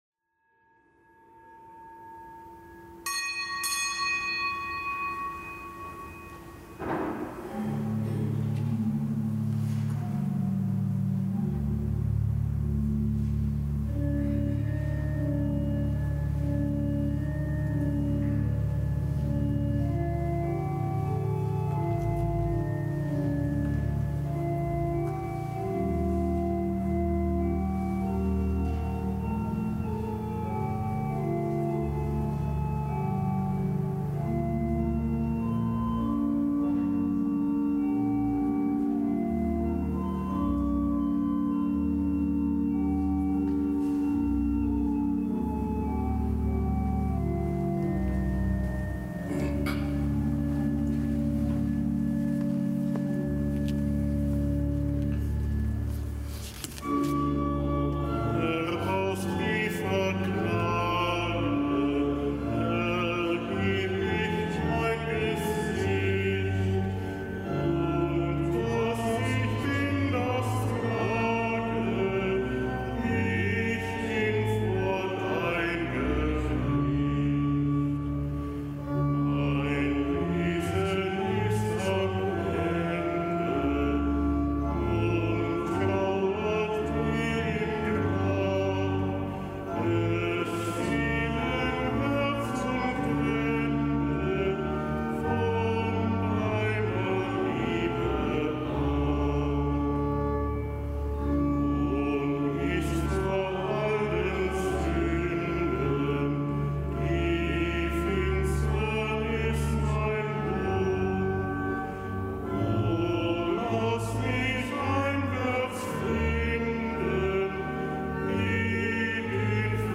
Kapitelsmesse aus dem Kölner Dom am Freitag der ersten Fastenwoche.